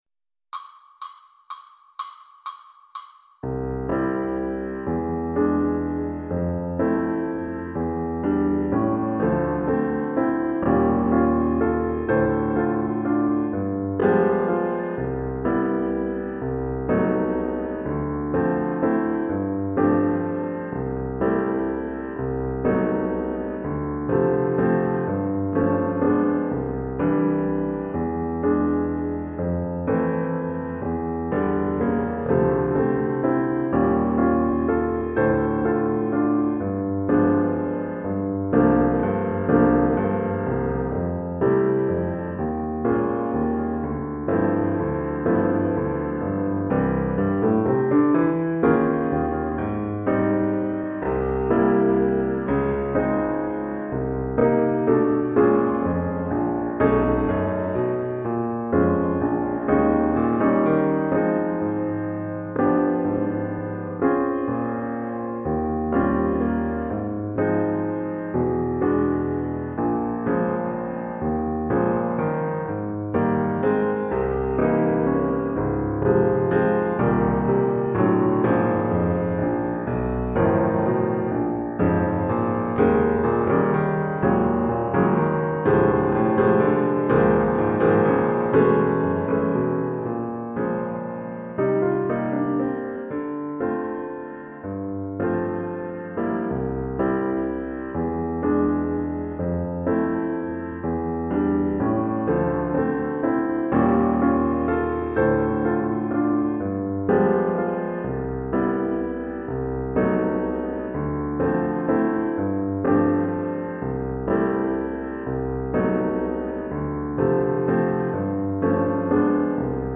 Valse de Lions piano slow